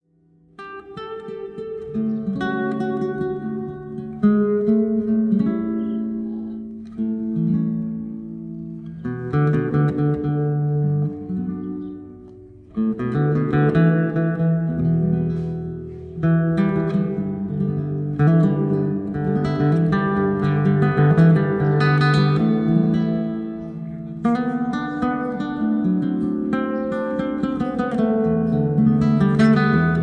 Solo Guitar Standards
Soothing and Relaxing Guitar Music